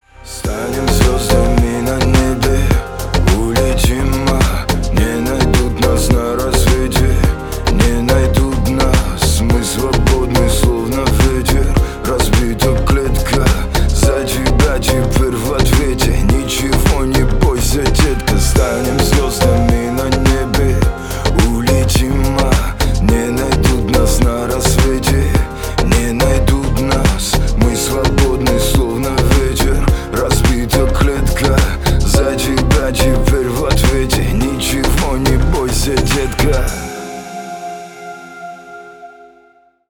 • Качество: 320 kbps, Stereo
Поп Музыка
кавказские
грустные